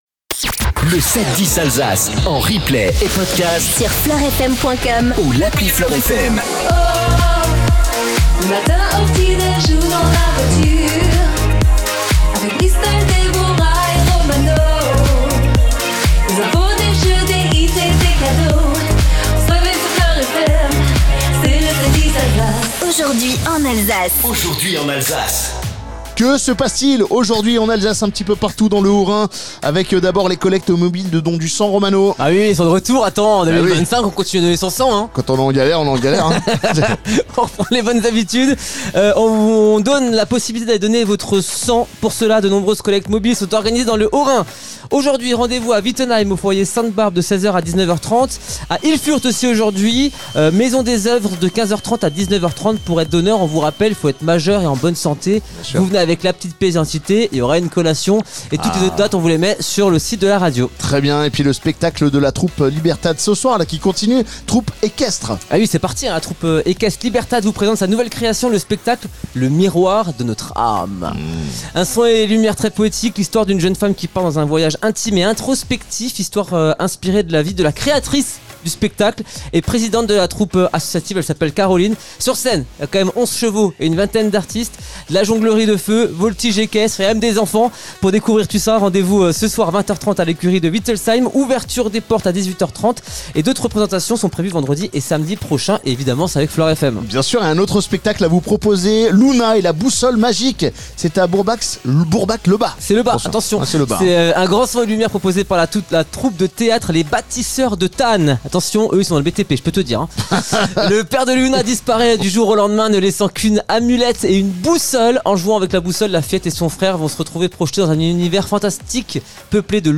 710 ALSACE FLOR FM MORNING PODCAST CERNAY GUEBWILLER LK TOURS CREDIT MUTUEL NATURABOIS FLORFM Mercredi 27 août 0:00 20 min 2 sec 27 août 2025 - 20 min 2 sec LE 7-10 DU 27 AOÜT Retrouvez les meilleurs moments du 7-10 Alsace Tour 2025, ce mercredi 27 août dans les rues de Cernay/ Guebwiller.